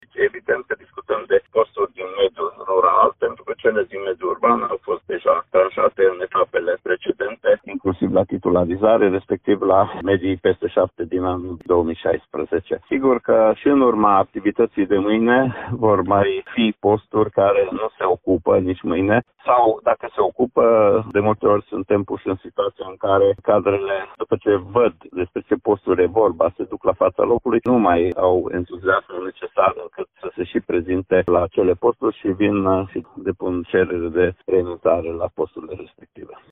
Inspectorul școlar general al județului Mureș, Ștefan Someșan, a precizat că este vorba mai ales despre locuri disponibile în mediul rural, care de ani de zile rămân neocupate: